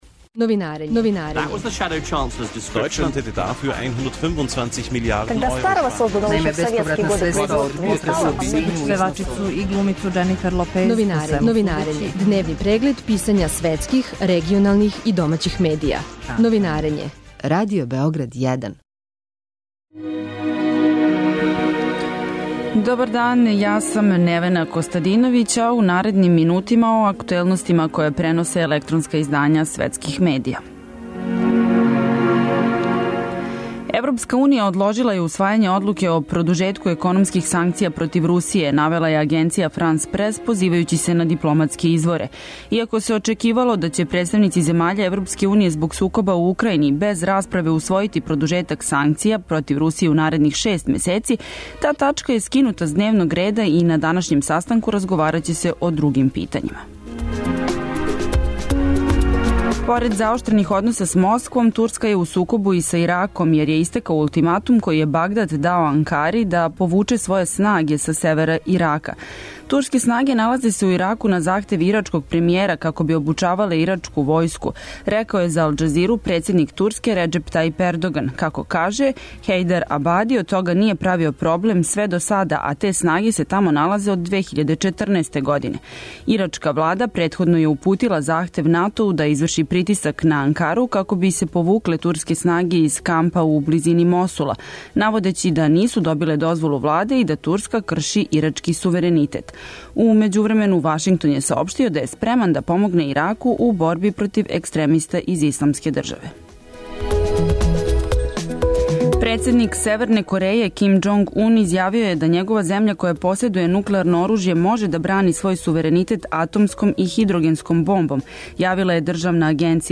Гост у студију